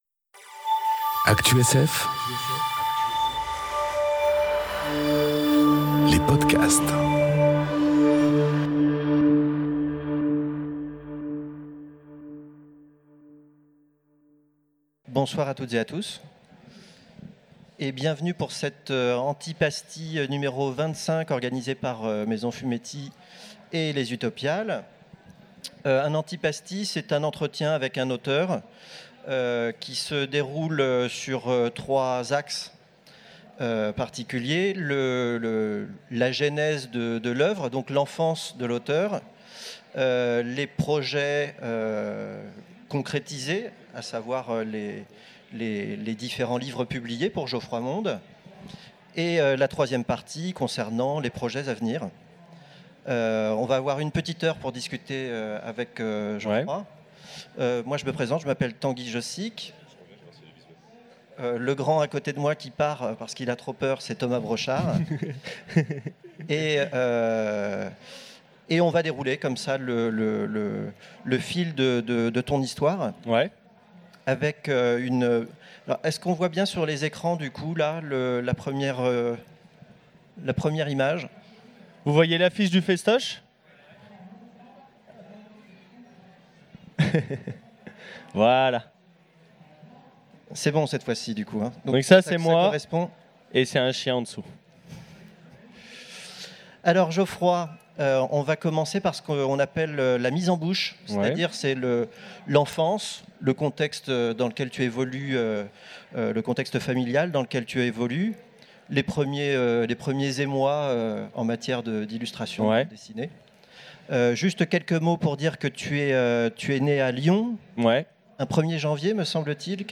Utopiales 2018 : Conférence Antipasti